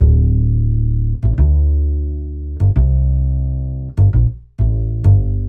XXL原声低音炮 1 127
标签： 贝斯 原声 爵士
声道立体声